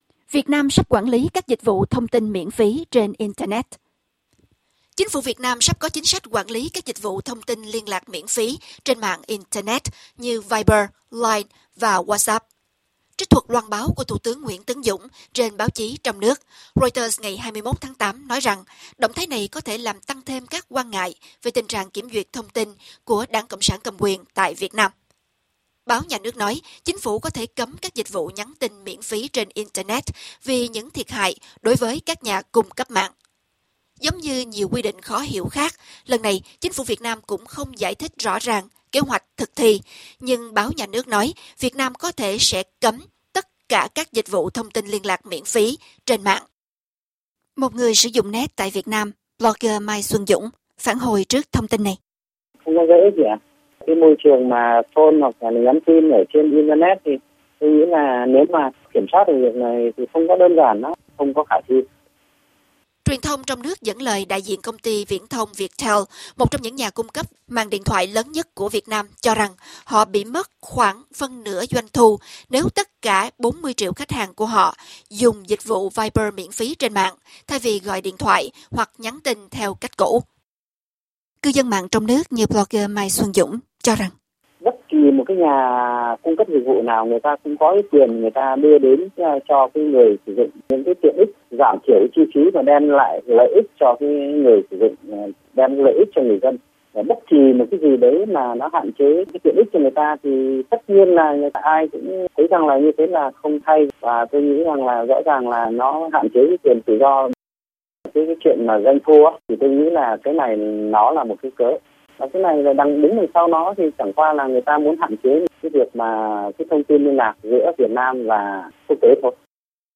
Nguồn: Reuters/Tuoitre/VOA Interview